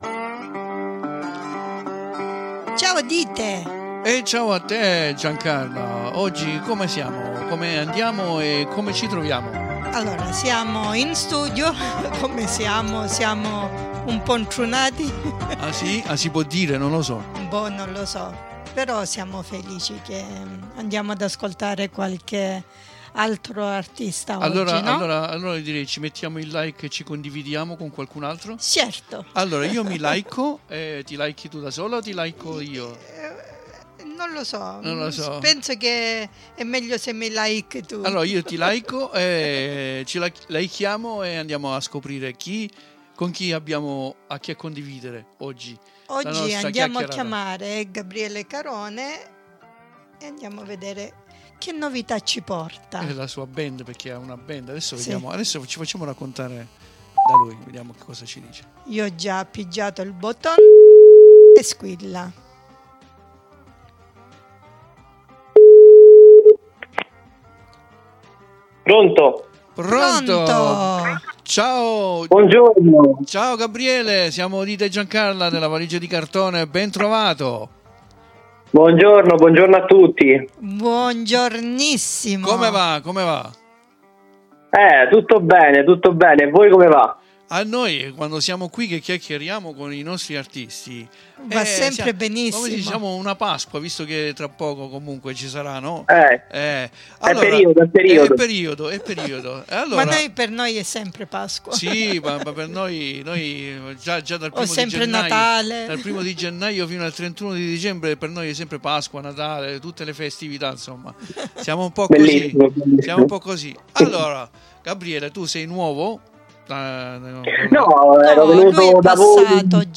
ABBASTANZA AMMIREVOLE COME PERSONA E NON VI VOGLIO SVELARE TROPPO, QUINDI VI CONSIGLIO DI ASCOLTARE LA SUA INTERVISTA CONDIVISA QUI IN DESCRIZIONE !